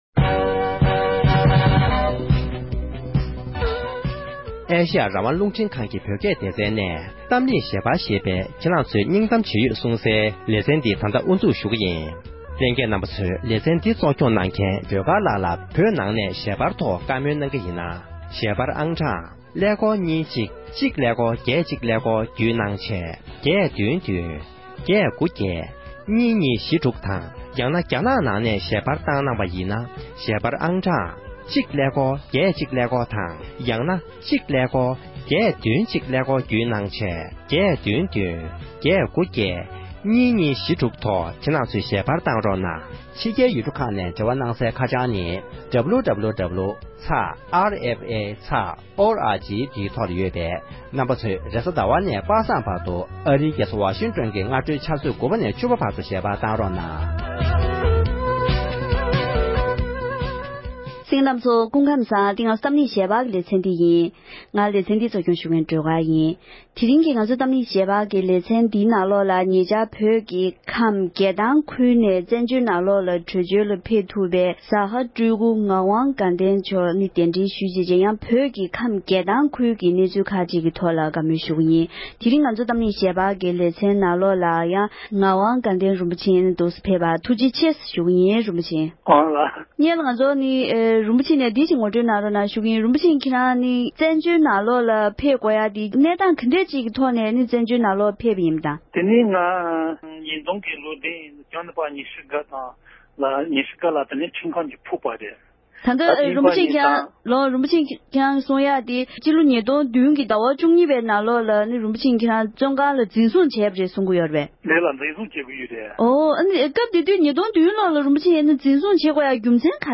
བོད་ཁམས་རྒྱལ་ཐང་ནས་བྲོས་བྱོལ་དུ་ཕེབས་པའི་མི་སྣ་ཞིག་གིས་བོད་རྒྱལ་ཐང་ཁུལ་གྱི་གནས་སྟངས་ངོ་སྤྲོད་གནང་བ།